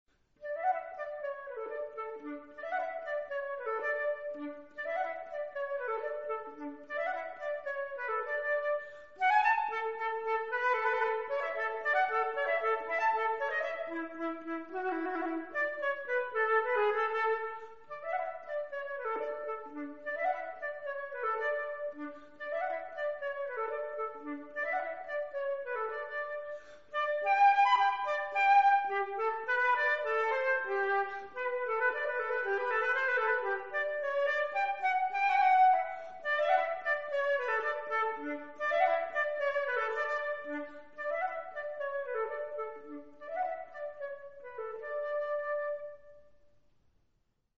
flute
"solo flute pieces"